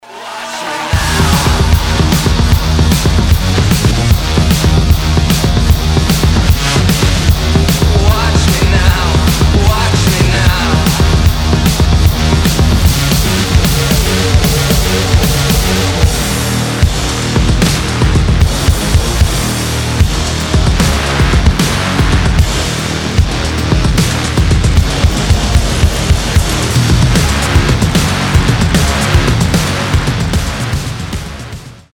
big beat , breakbeat , electronic rock